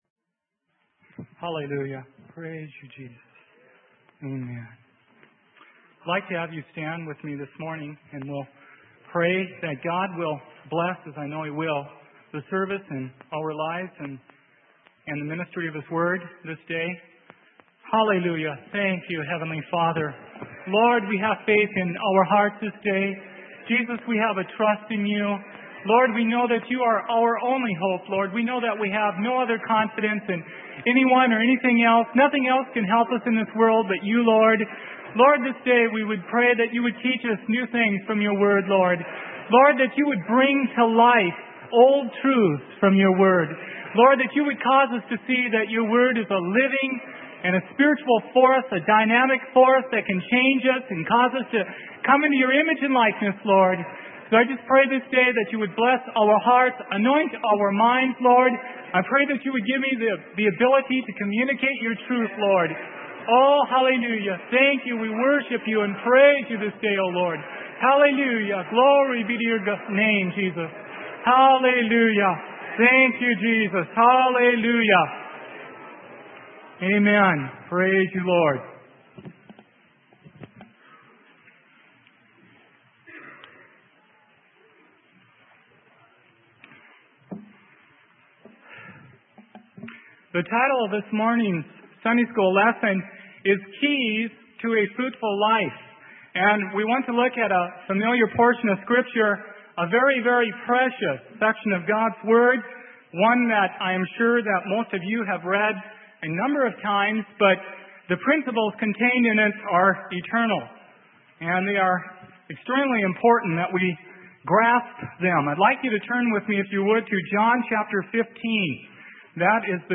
Sermon: Key to a Fruitful Life - Freely Given Online Library